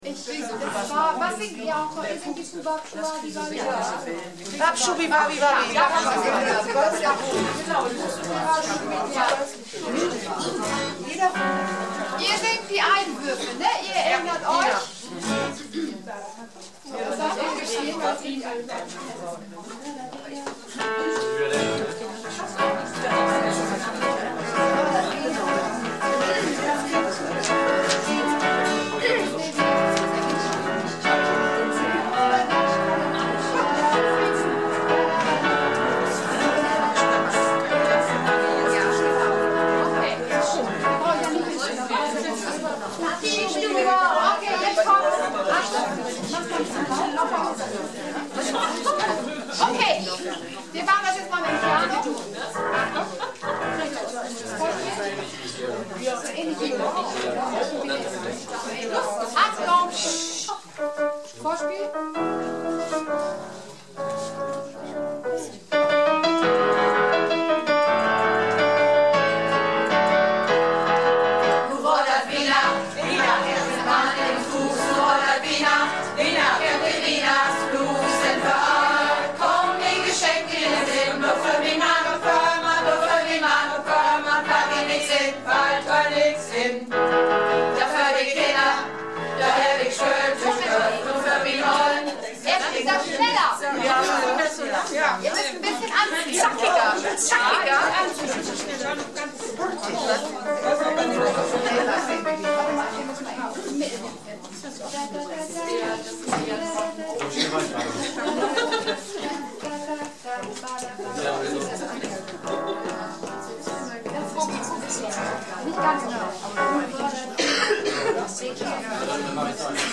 (Gesamtprobe)